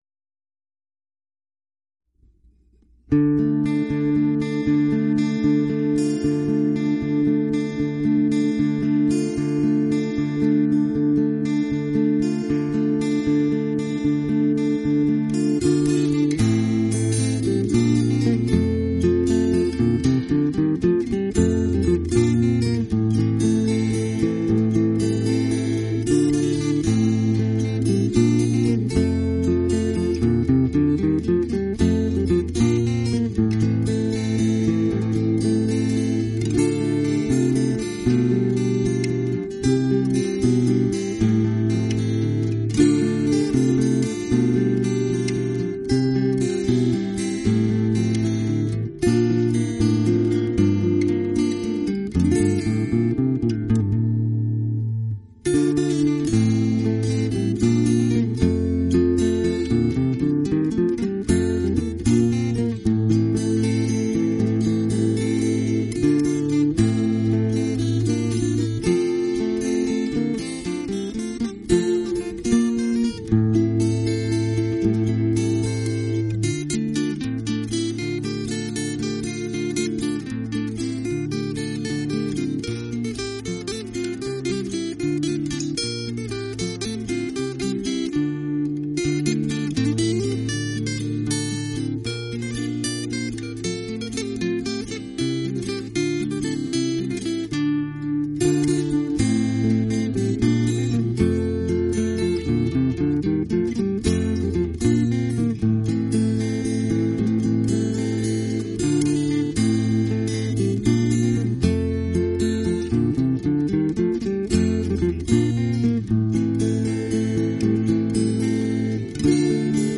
A.guitar
クラシックな仕上がりです。